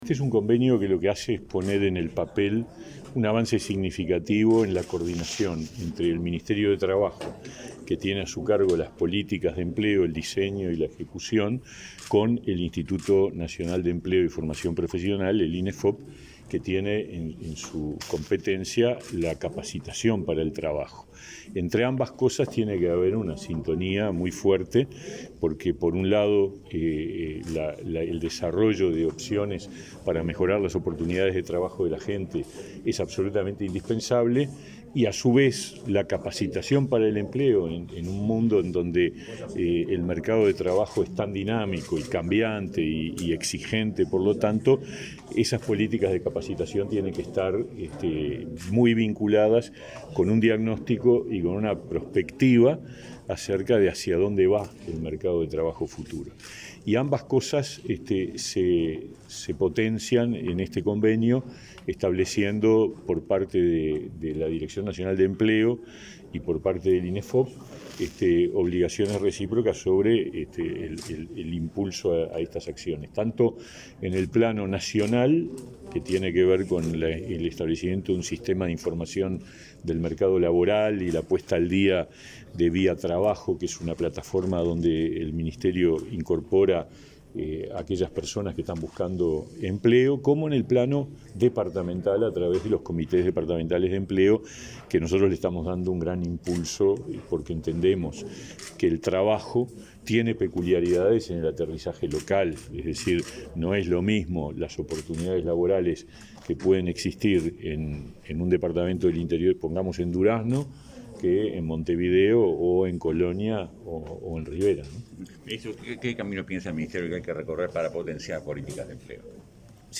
Declaraciones a la prensa del ministro de Trabajo y Seguridad Social, Pablo Mieres